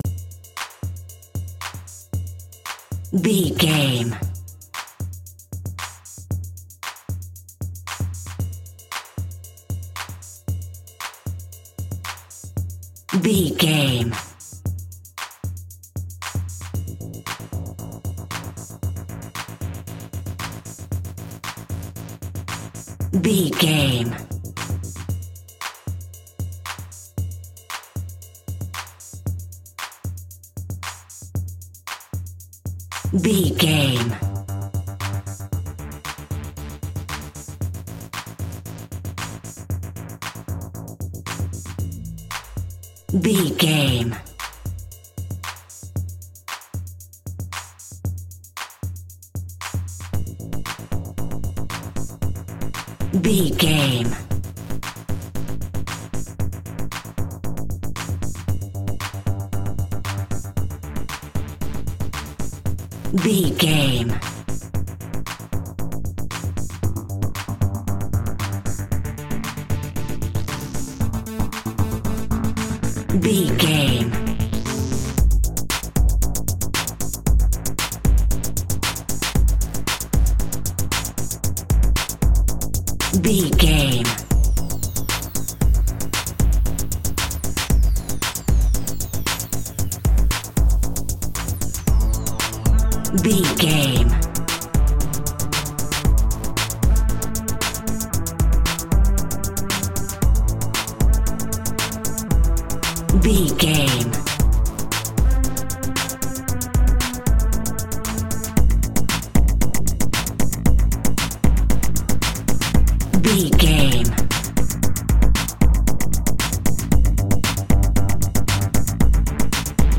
Aeolian/Minor
electronic
techno
trance
industrial
drone
glitch
synth lead
synth bass